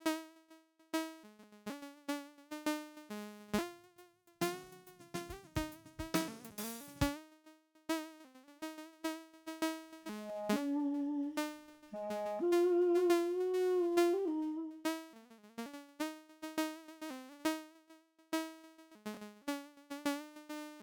We gaan nu de pitch tracken van een sample met melodie, om daarmee de frequentie van de zaagtand aan te sturen.